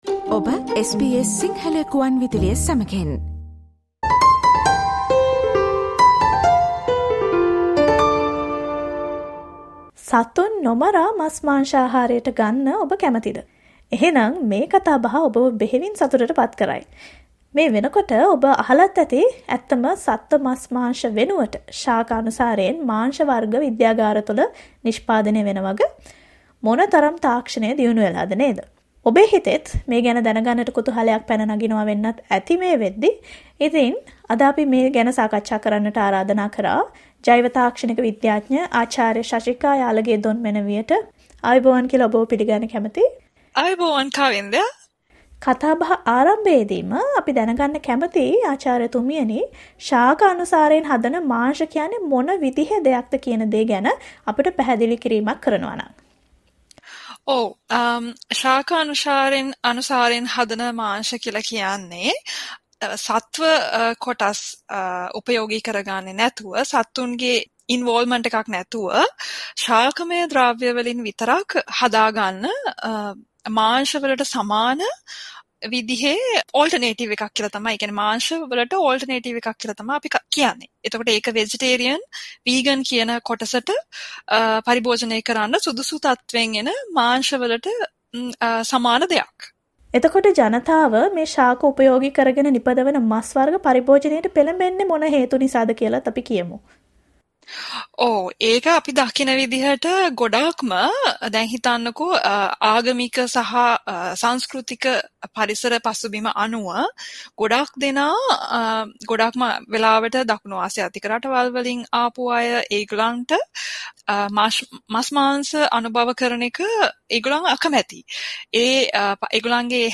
So this conversation will make you very happy. By now you've heard that plant-based meats are produced in laboratories instead of real animal meat.